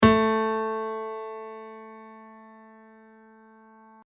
Я поднимаю уровень Sustain в этом примере:
Еще раз, измененная форма волны пиано: